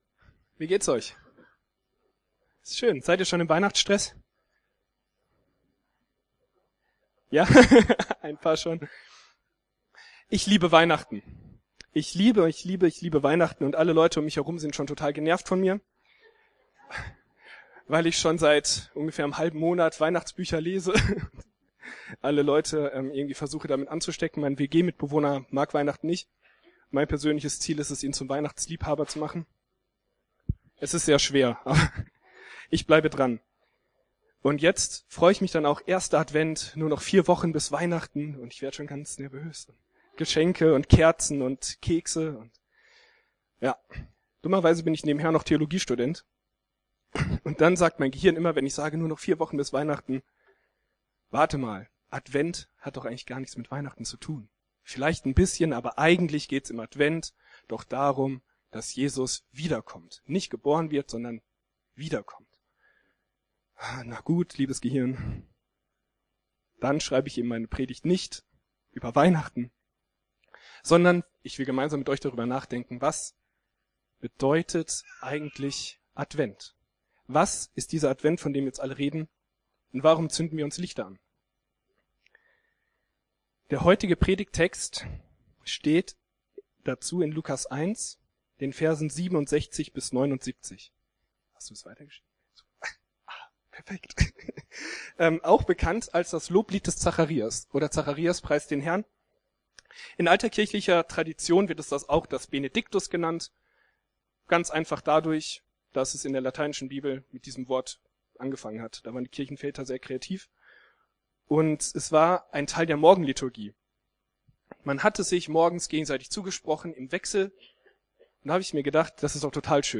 spricht darüber in seiner Predigt vom 1. Dezember 2013 über Lukas 1,67-79.